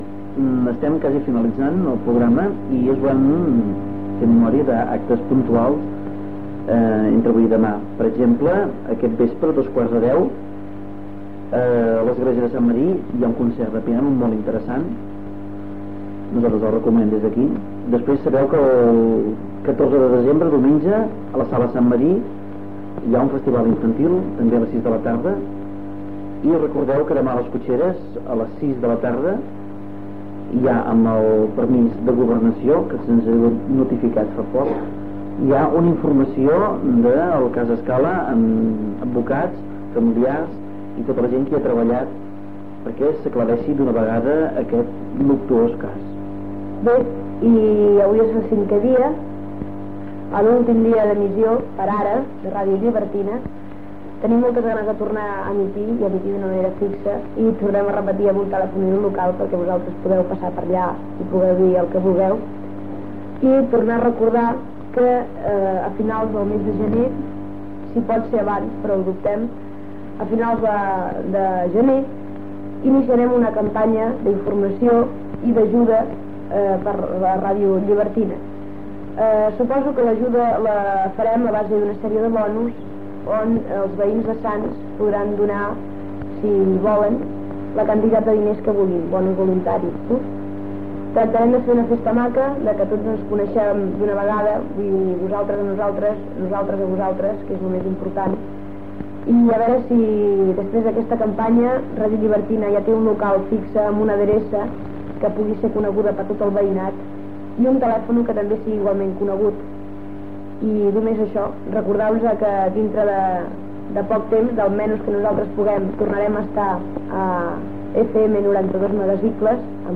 FM
Emissora lliure del barri de Sants.